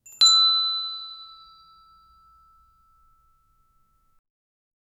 Gentle completion chime, single soft bell tone - satisfying "done" feeling
-gentle-completion-chime--65suf3wp.wav